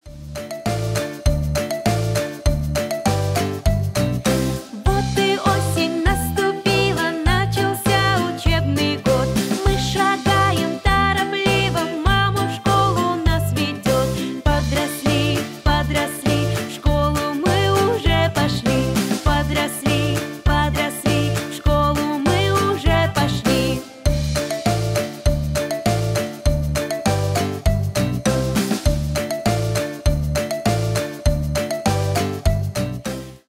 Дети
весёлые